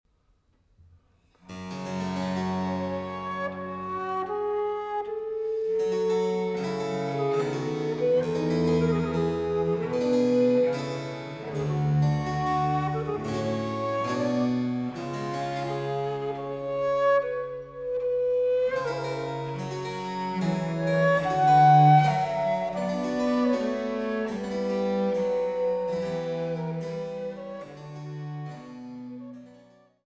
Cembalo
Melodieusement